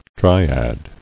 [dry·ad]